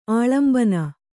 ♪ āḷambana